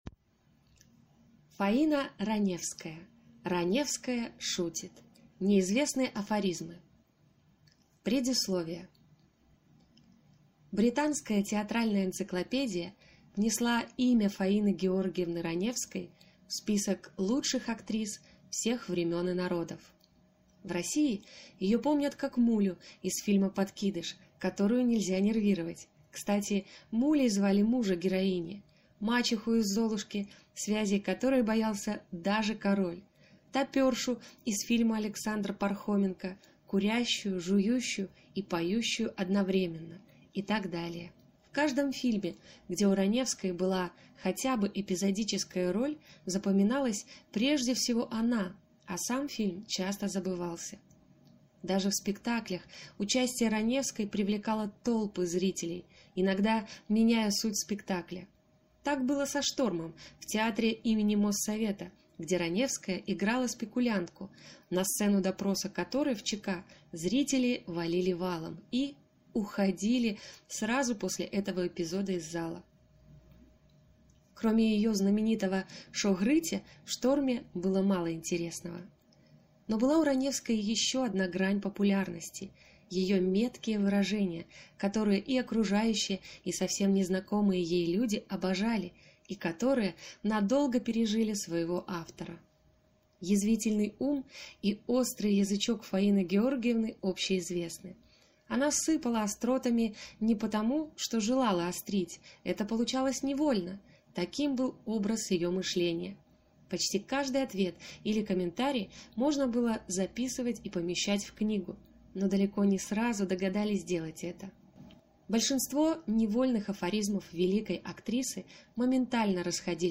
Аудиокнига Раневская шутит. Неизвестные афоризмы | Библиотека аудиокниг